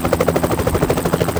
Build and run to hear the helicopter sound, which at the moment will be a bit too lound.
heli.wav